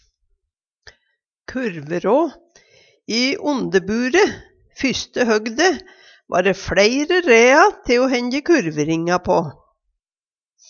kurverå - Numedalsmål (en-US)